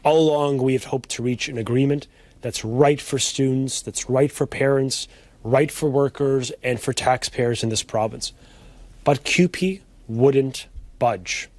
Education Minister Stephen Lecce told reporters that in the government’s view, the union is to blame for the legislation being passed.